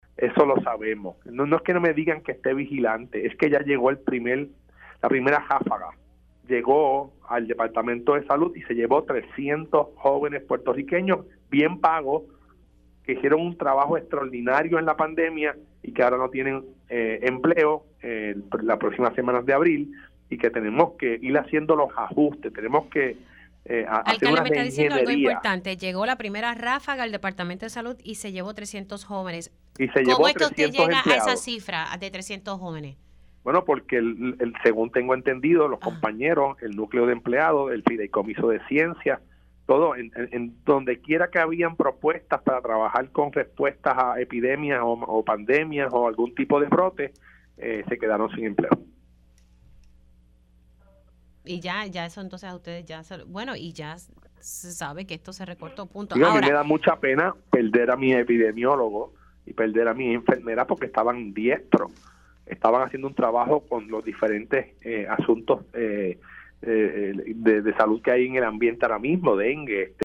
El alcalde de Coamo, Juan Carlos ‘Tato’ García Padilla informó en Pega’os en la Mañana que la pérdida de 45 millones de dólares en fondos federales resultará en el despido de 300 empleados de salud.